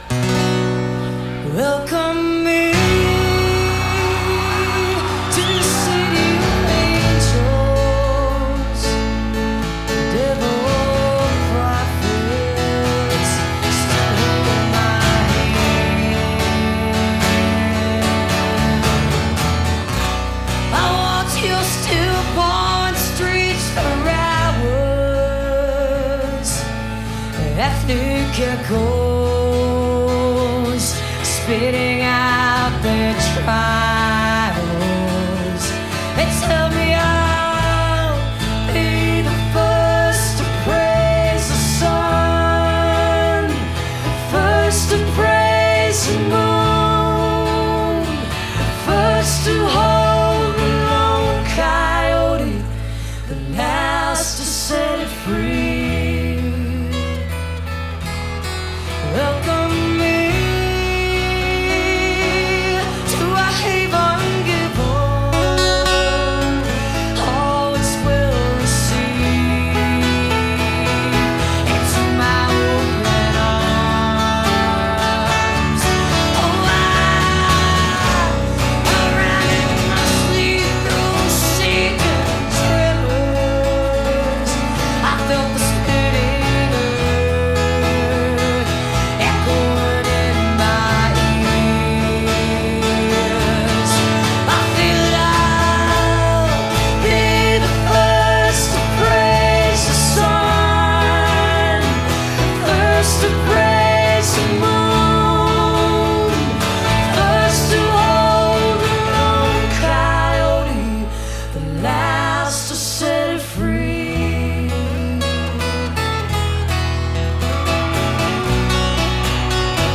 acoustic duo